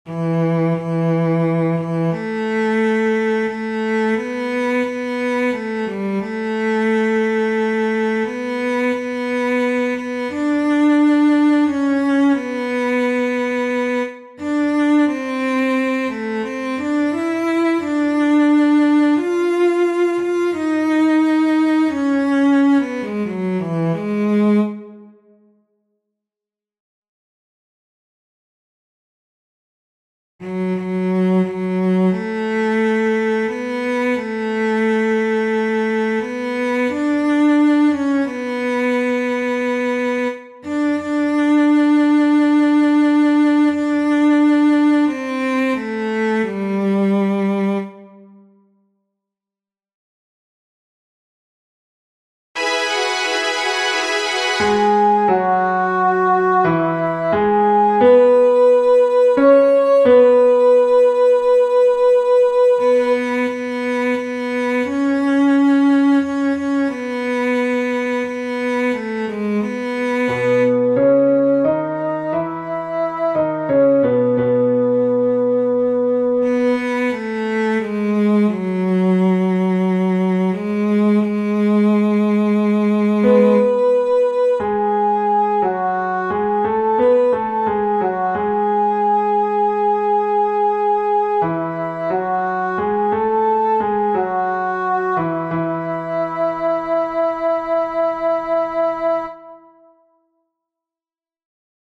These begin two measures before letter D. The choir entrance is just after 0:50.
five_mystical_songs_3-alto.mp3